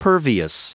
Prononciation du mot pervious en anglais (fichier audio)
Prononciation du mot : pervious